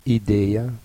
klik op het woord om de uitspraak te beluisteren